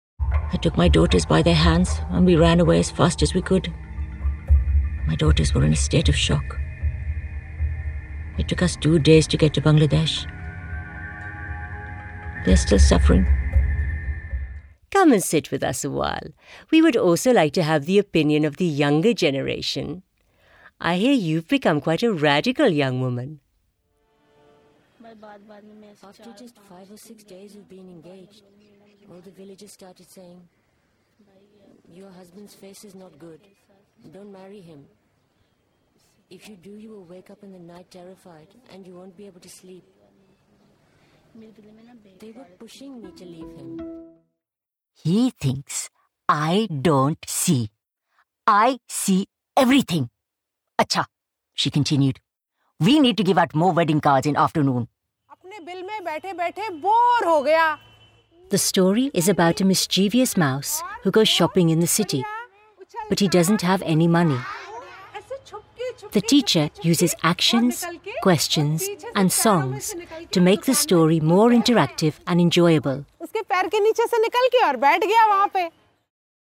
RP + British Indian (Hindi, Punjabi). Friendly, warm, reassuring, youthful, approachable, natural | Rhubarb Voices